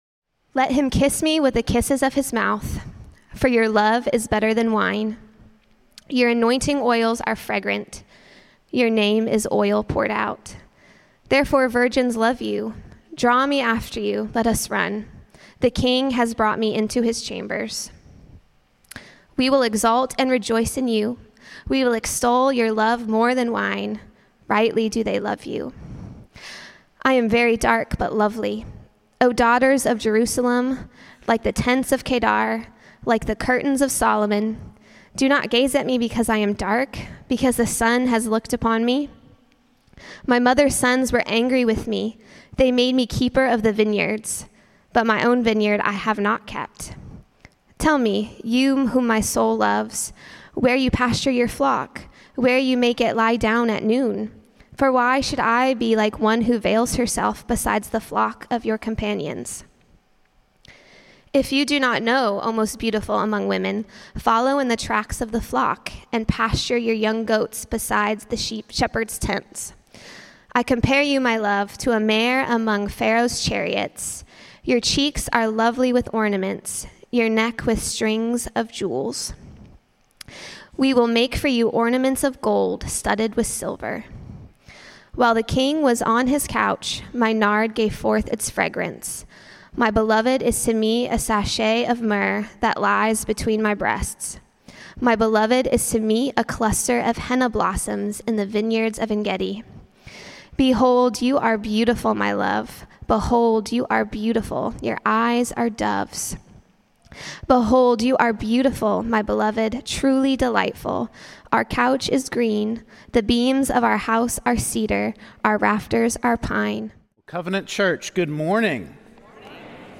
Service